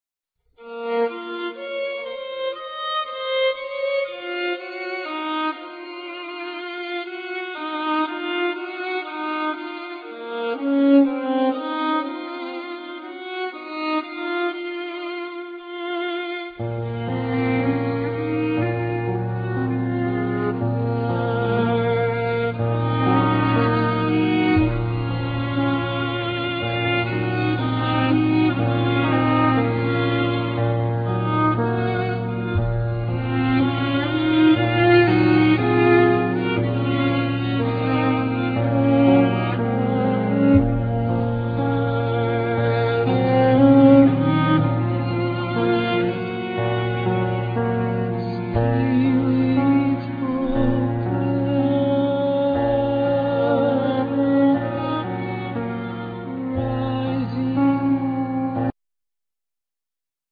Piano
Voice
Violin